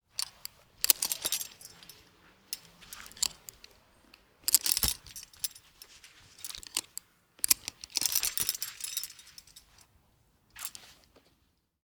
towbar_hookingSound.ogg